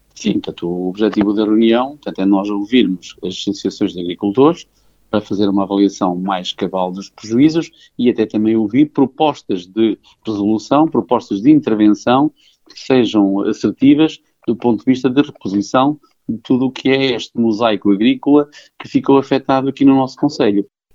Está marcada para esta sexta-feira uma reunião entre Município de Mirandela e as Organizações de Agricultores e Juntas de Freguesia das áreas afetadas pelo incêndio que assolou o concelho nos últimos dias. O objetivo é avaliar prejuízos, adianta o presidente de câmara de Mirandela, Vítor Correia: